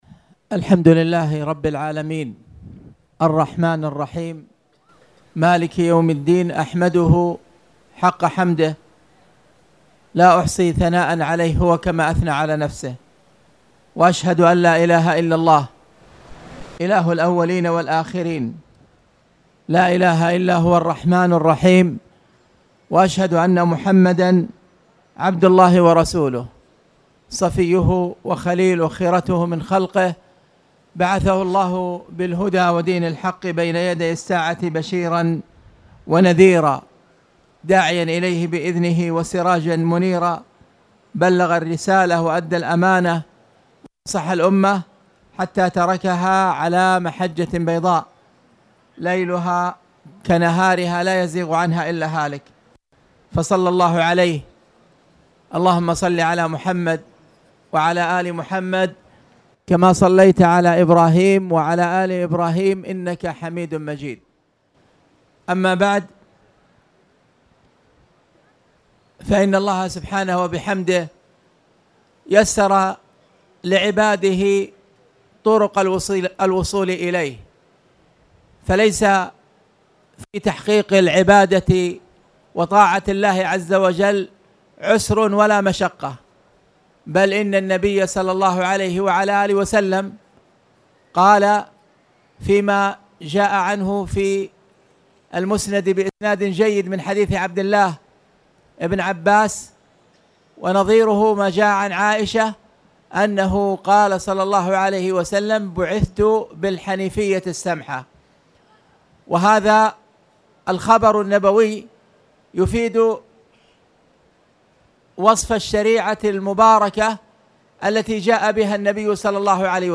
تاريخ النشر ١٥ صفر ١٤٣٨ هـ المكان: المسجد الحرام الشيخ